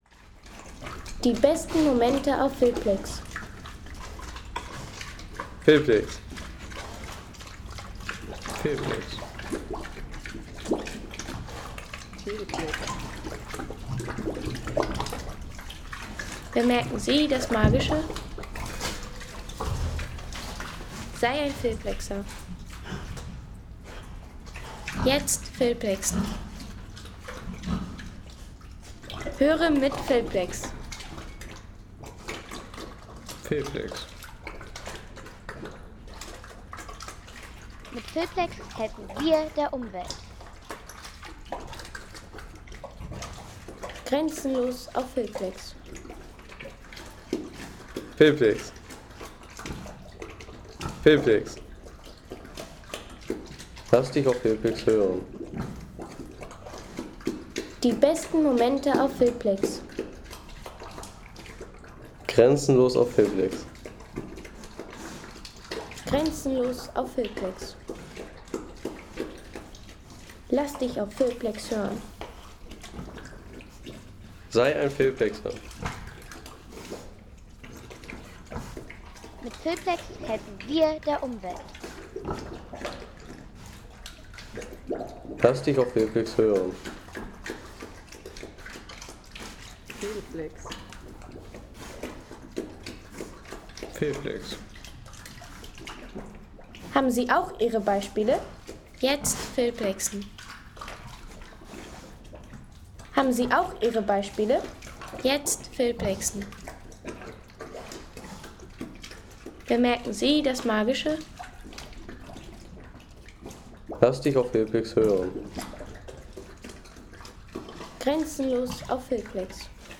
Schmatzen der Schweine
Tierwelt - Bauernhof-Tiere
Appetitliches Schmatzen – Die Schweine genießen ihr Festmahl.